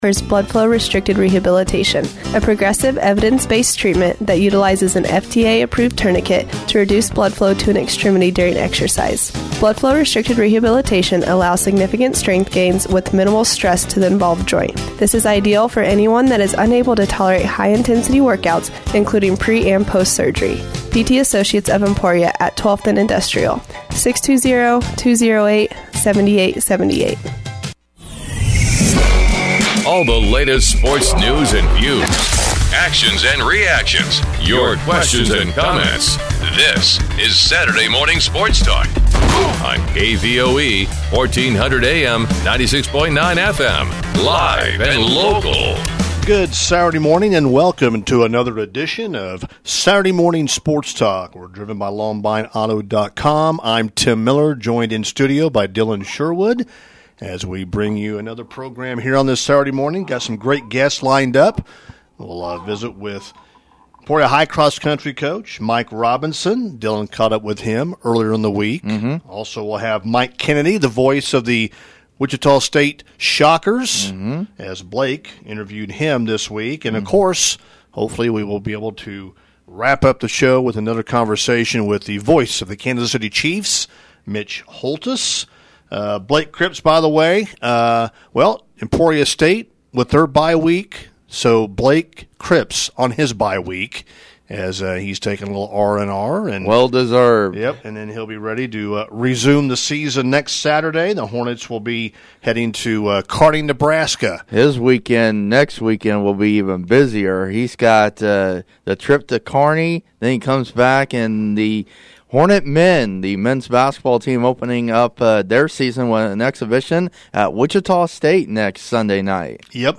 Saturday Morning Sports Talk